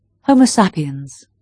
Hur uttalas ordet människa ? [ˈmɛnɪɧa]